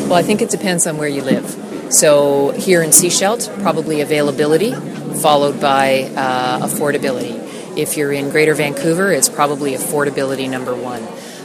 Speaking at a weekend housing forum in Sechelt, Mackenzie pointed out availability is often the biggest issue in smaller centres.